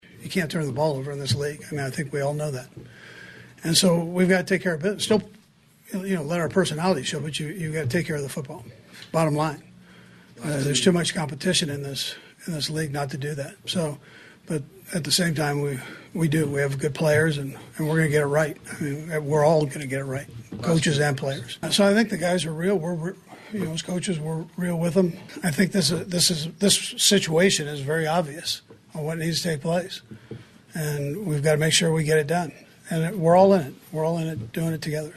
Kansas City Chiefs Coach Andy Reid met with the media Wednesday.